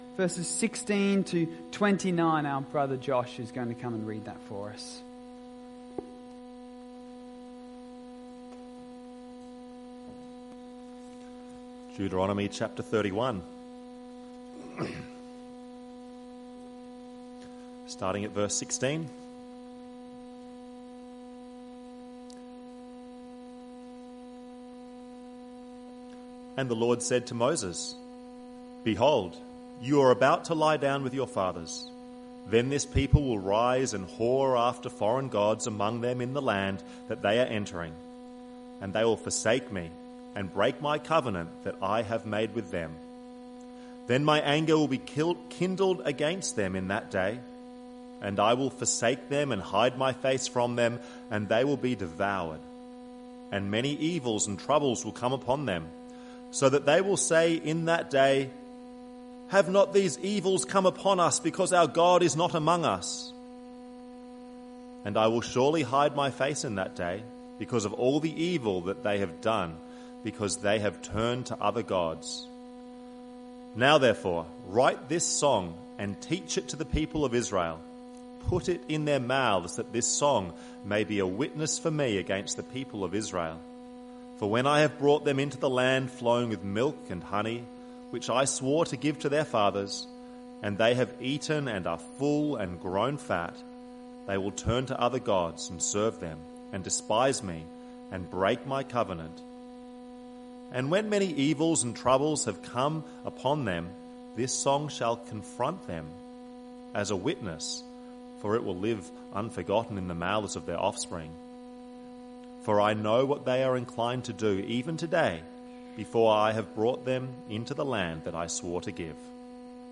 Evening Service - 18th January 2026